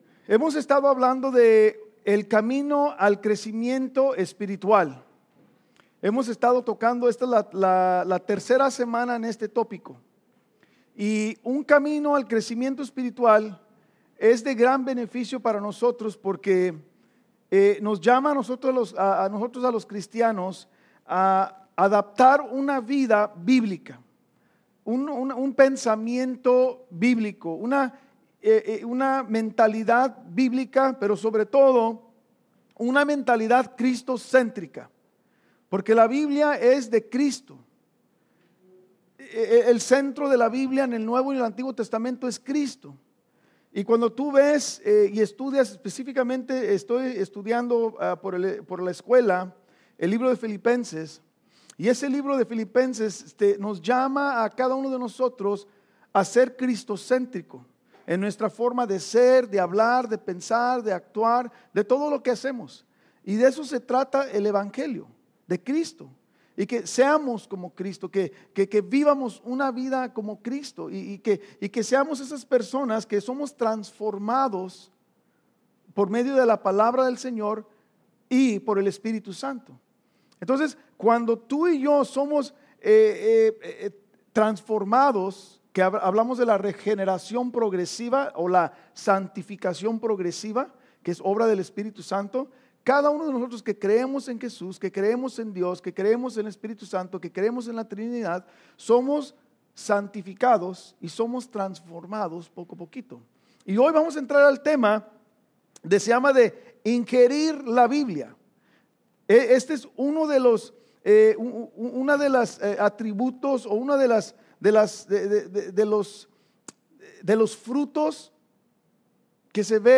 Estudio Biblico | Iglesia Vida Hammond